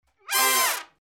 So the first hit was octaves and the second hit was voiced out.
Arranger stuff: It’s a C7 chord.